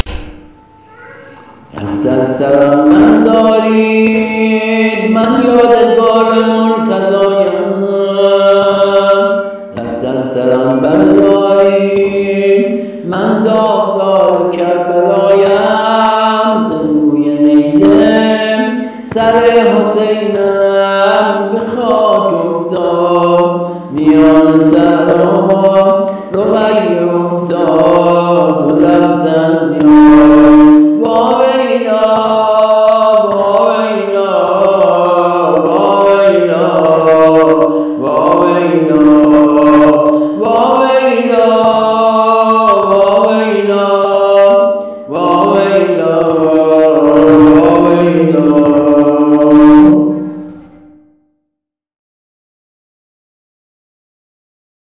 ◾با سبک و ملودی جدید